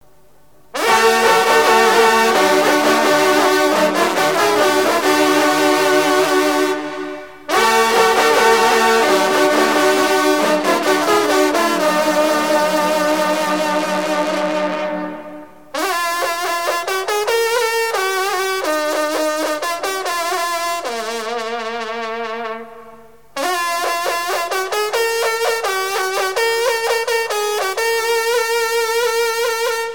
rencontre de sonneurs de trompe
Pièce musicale éditée